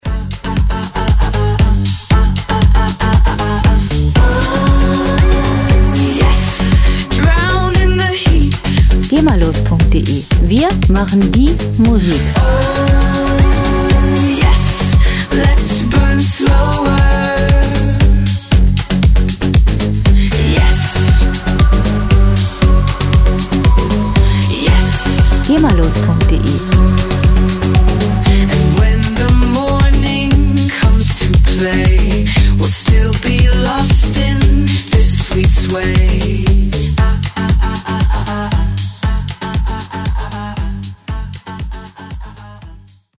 Musikstil: Pop
Tempo: 117 bpm
Tonart: Fis-Moll
Charakter: lüstern, antörnend
Instrumentierung: Popsängerin, Synthesizer, E-Gitarre